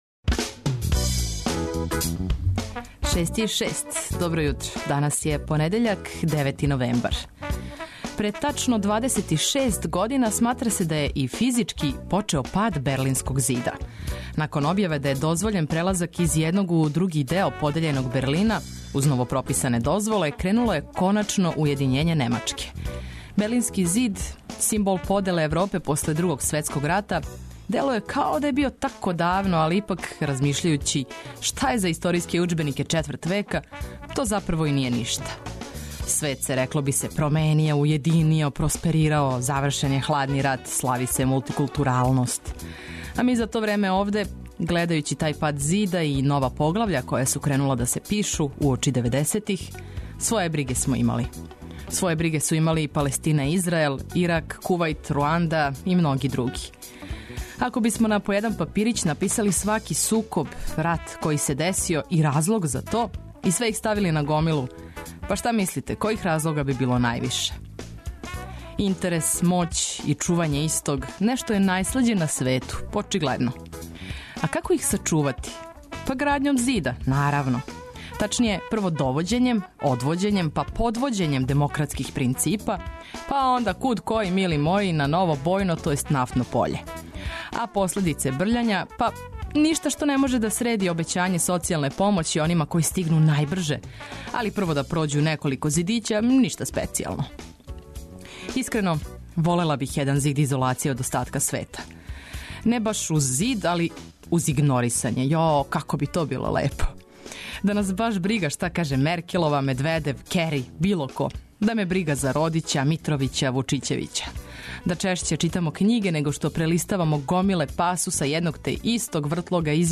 Водитељ: